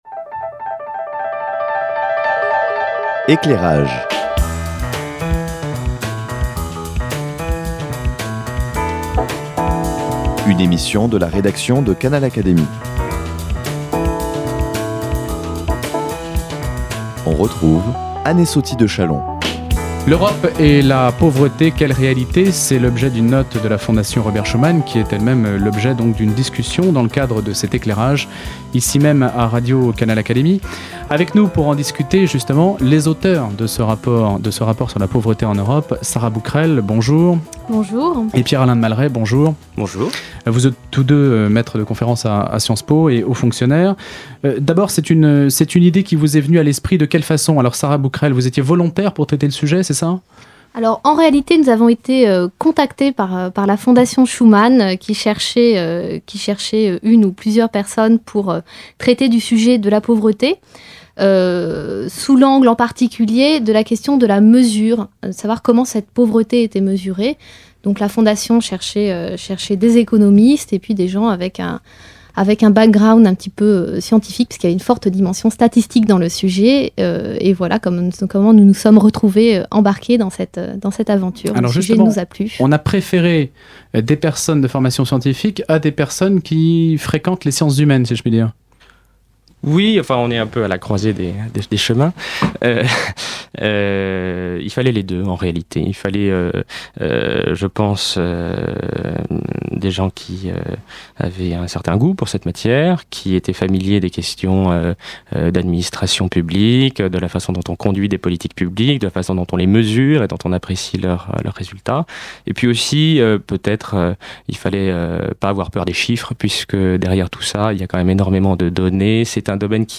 Dans cet entretien, les auteurs expliquent toutes les nuances que recouvre ce phénomène, car on n'est pas pauvre « de la même manière » au Luxembourg, au Portugal, en République tchèque ou en Irlande.